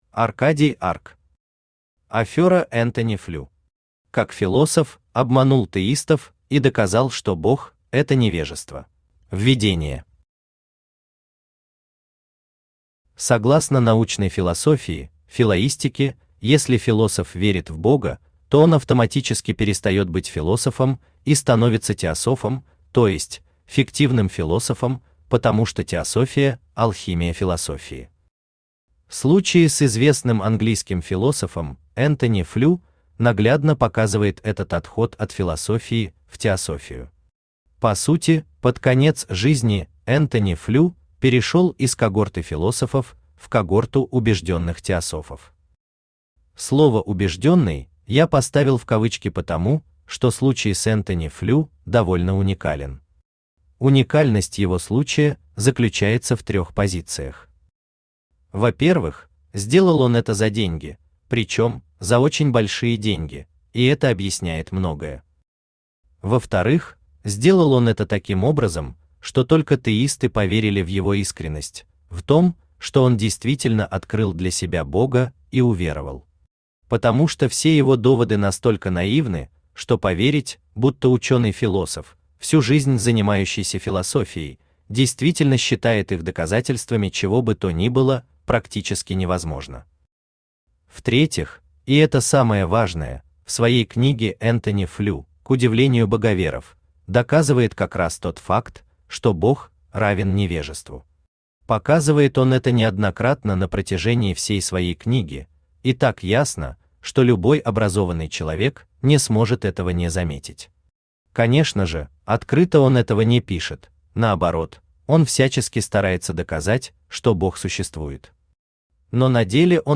Аудиокнига Афёра Энтони Флю.
Как философ обманул теистов и доказал, что бог – это невежество Автор Аркадий Арк Читает аудиокнигу Авточтец ЛитРес.